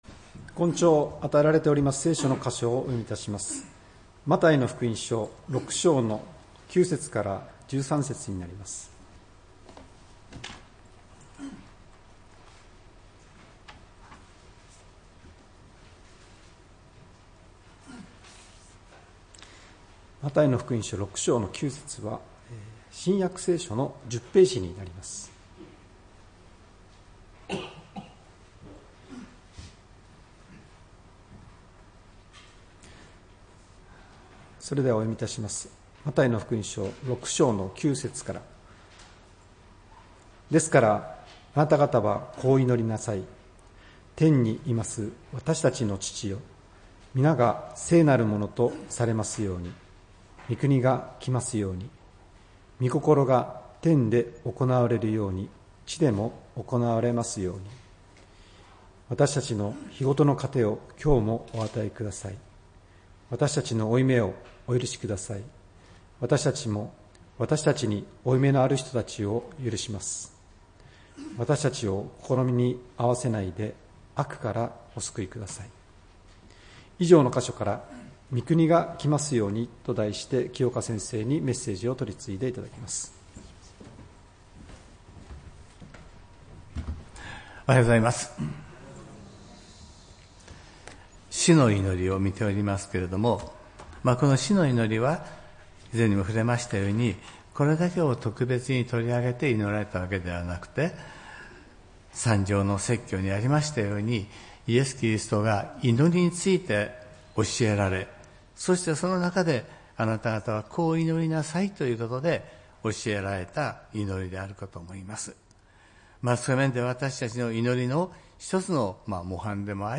礼拝メッセージ「み国がきますように」(１月５日）